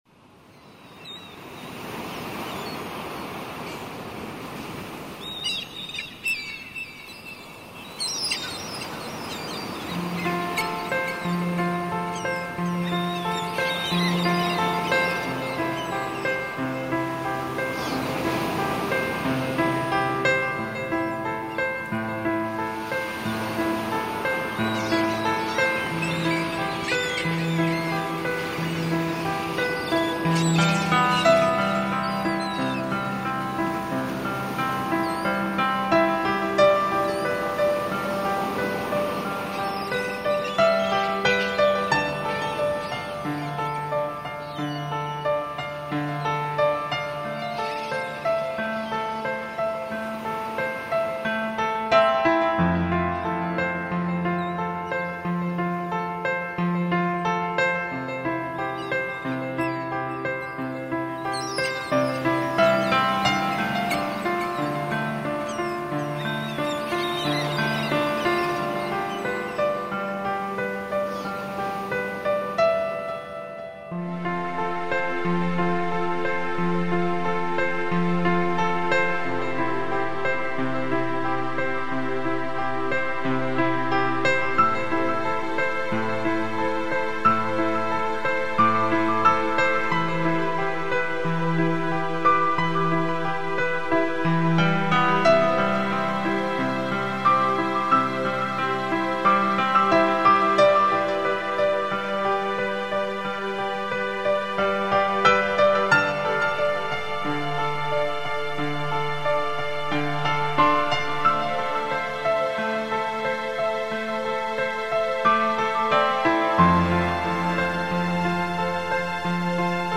bez_imeni_shum_okeana.mp3